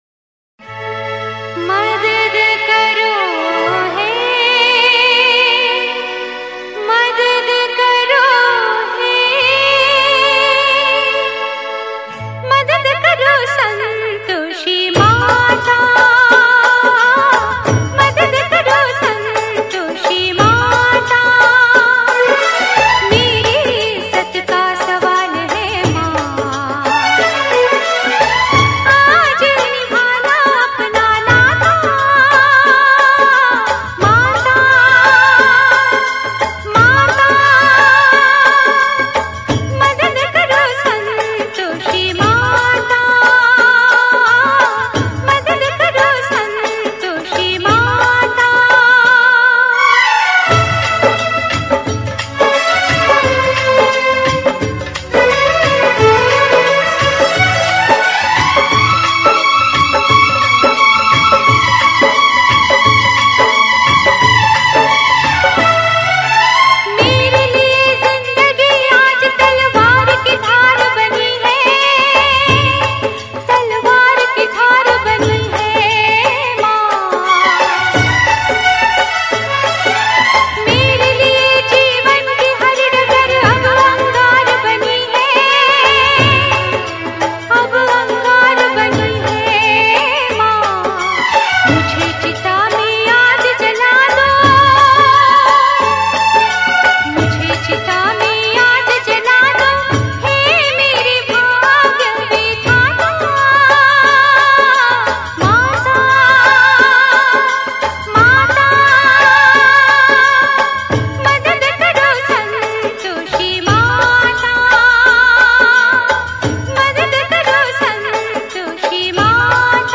Devotional Single Songs - Bhajans